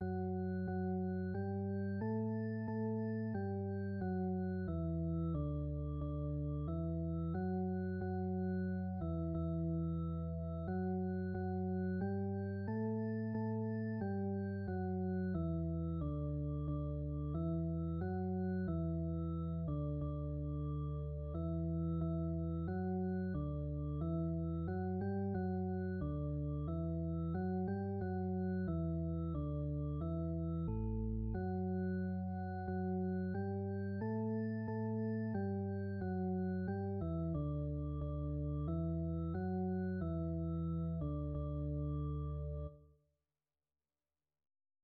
Műfaj komolyzenei dal
A kotta hangneme D dúr
Előadásmód Allegro assai